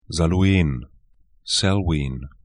Pronunciation
Salween   za'lŭe:n